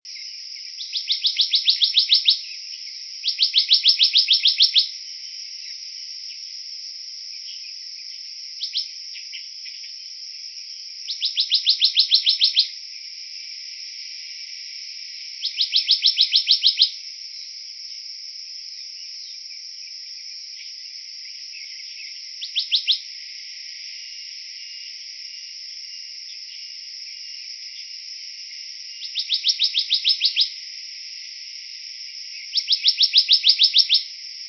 54-4鳥松2013黑枕藍鶲背不藍2.WAV
黑枕藍鶲(台灣亞種) Hypothymis azurea oberholseri
錄音地點 高雄市 鳥松區 鳥松濕地
錄音環境 雜木林
鳴叫鳥背部不藍疑似母鳥
錄音: 廠牌 Denon Portable IC Recorder 型號 DN-F20R 收音: 廠牌 Sennheiser 型號 ME 67